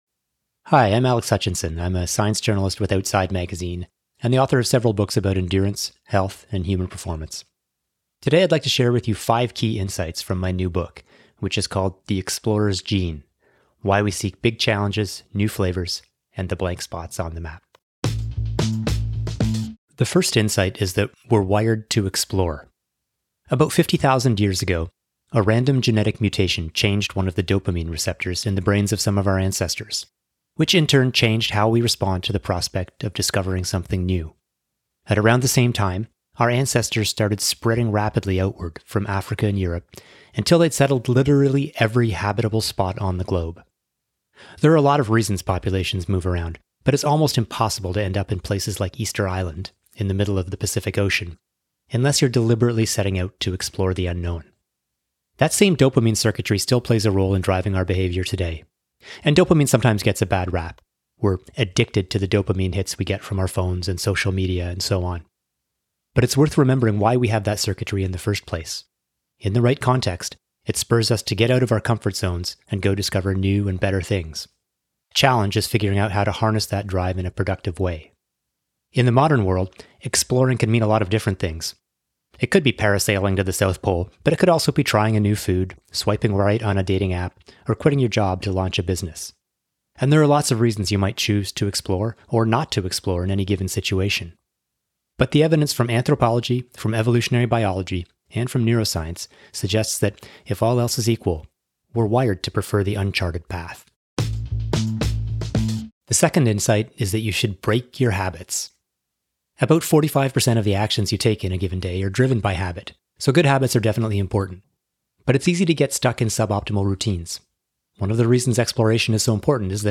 Below, Alex shares five key insights from his new book, The Explorer’s Gene: Why We Seek Big Challenges, New Flavors, and the Blank Spots on the Map. Listen to the audio version—read by Alex himself—in the Next Big Idea App.